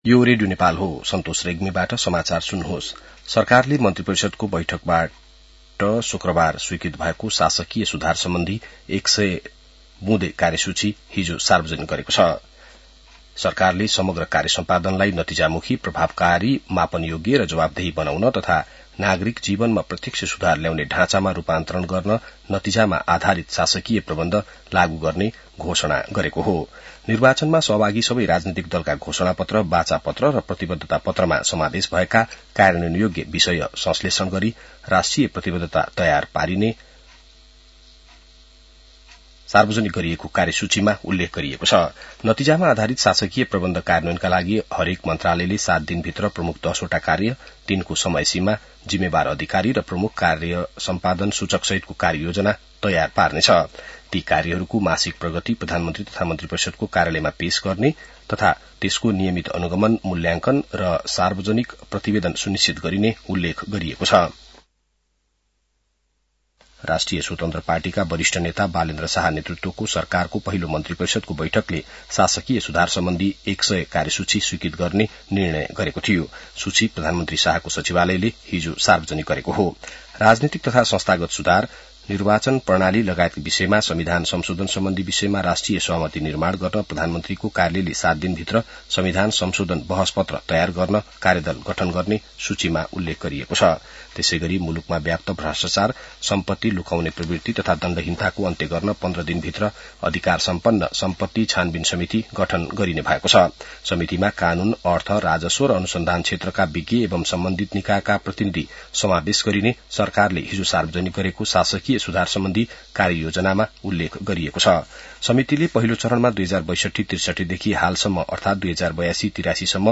बिहान ६ बजेको नेपाली समाचार : १५ चैत , २०८२